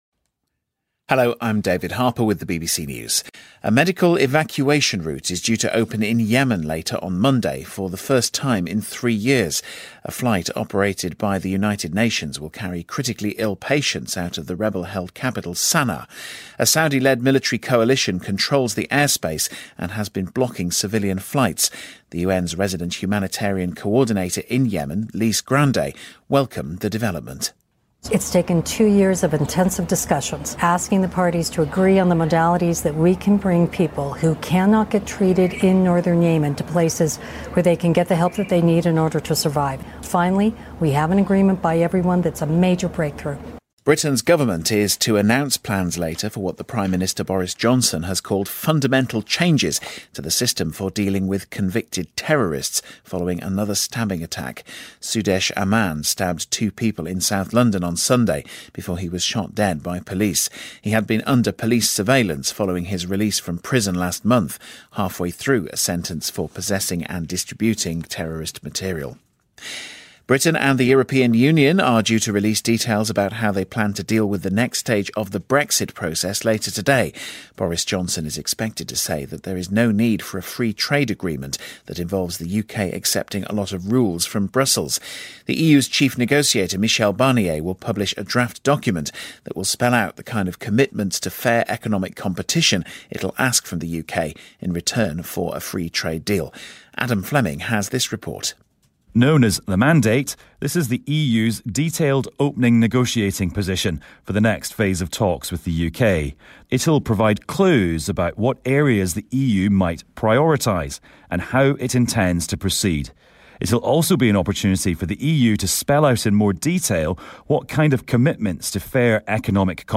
英音听力讲解:美国堪萨斯城酋长队夺得“超级碗”冠军|英音听力讲解